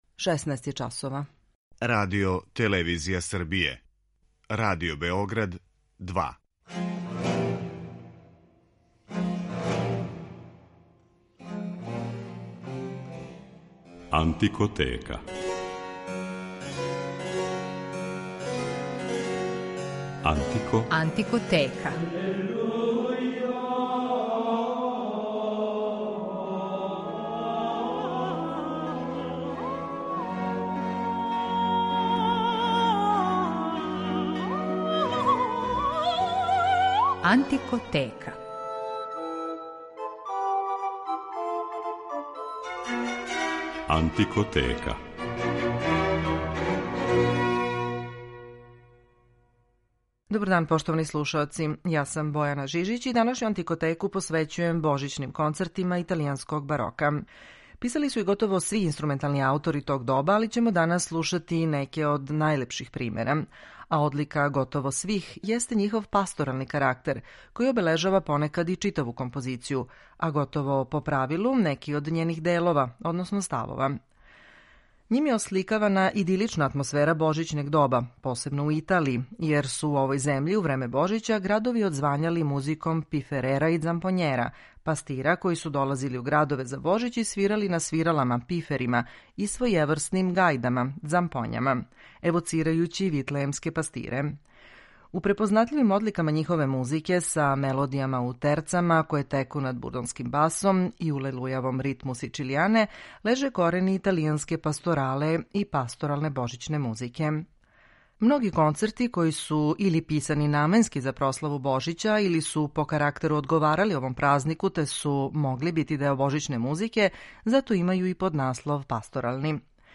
води слушаоце у свет ране музике и прати делатност уметника специјализованих за ову област који свирају на инструментима из епохе или њиховим копијама.
Неки од најлепших примера барокне концертантне музике јесу дела писана за Божић, а посебно значајан допринос овој литератури дали су италијански барокни мајстори. У данашњој емисији моћи ћете да слушате божићне концерте Арканђела Корелија, Франческа Манфрединија, Ђузепа Торелија, Пјетра Локателија и Антонија Вивалдија. О божићној музици говоримо и у рубрици „Антикоскоп", у којој ћемо представити једно од највреднијих и најимпозантнијих вокално-инструменталних дела барока ‒ Божићни ораторијум Јохана Себастијана Баха.